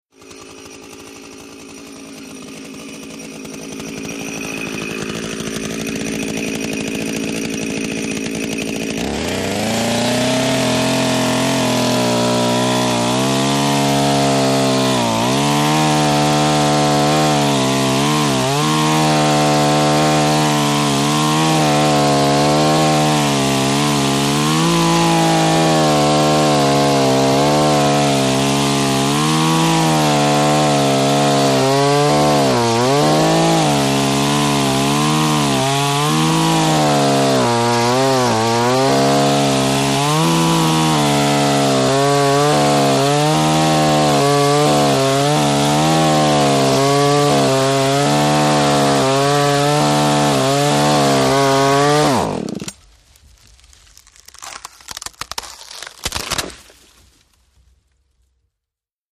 ChainsawCutsTree PE698901
MACHINES - CONSTRUCTION & FACTORY CHAINSAW: EXT: Cuts tree for 45 seconds, tree crack.